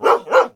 sounds / monsters / dog
bdog_idle_1.ogg